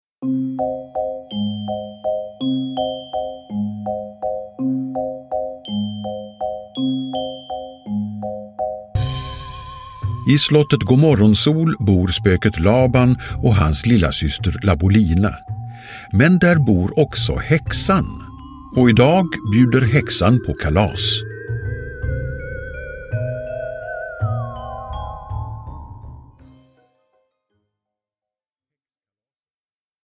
Lilla Spöket Laban: Häxkalaset – Ljudbok – Laddas ner